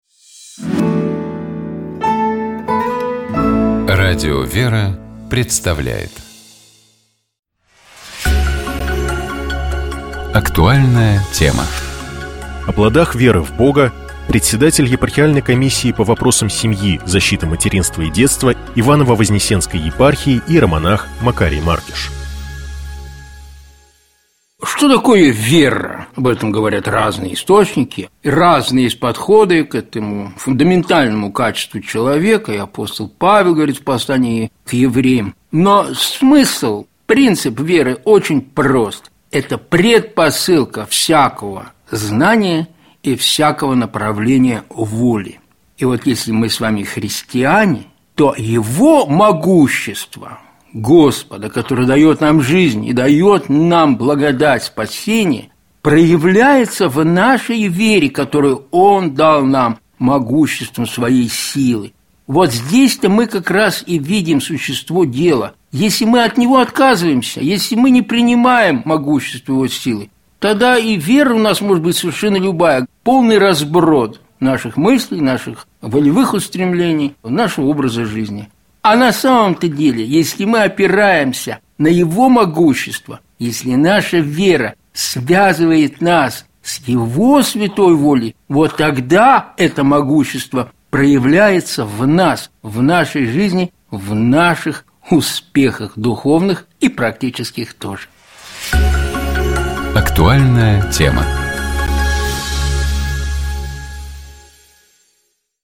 Комментарий (английский, мужской голос):